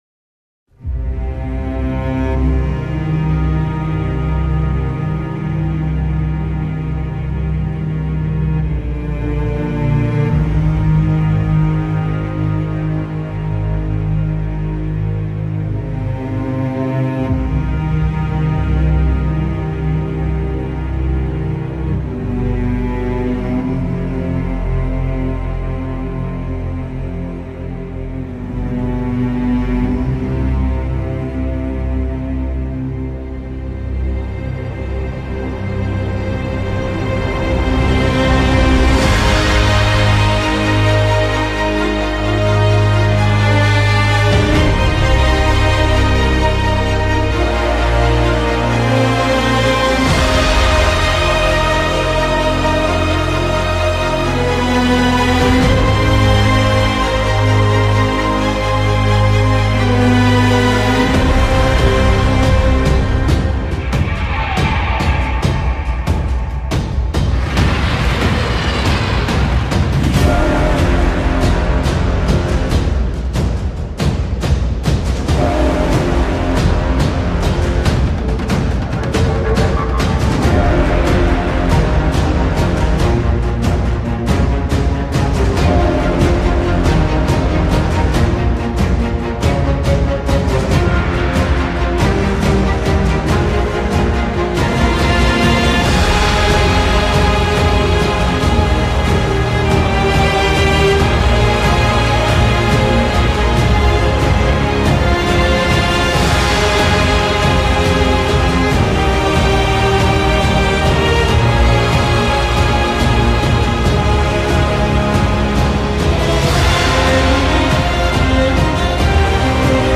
Live-Performance